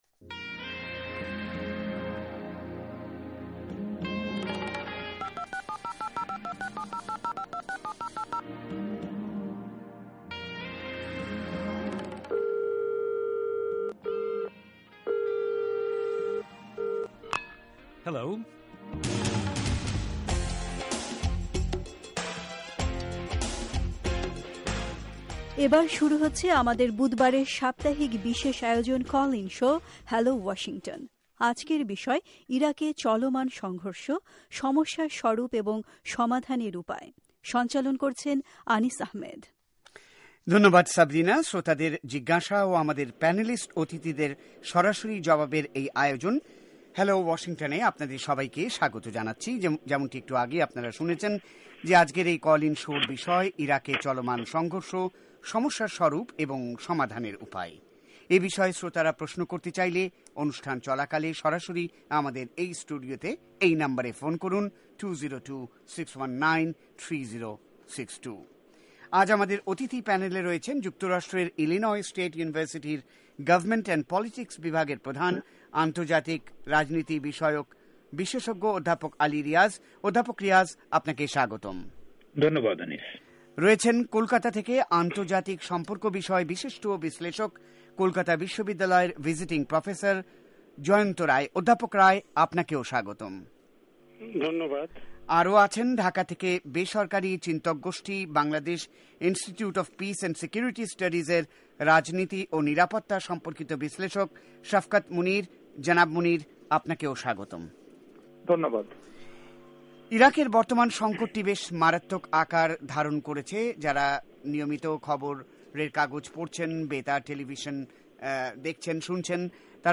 শুনুন আজকের কল ইন শো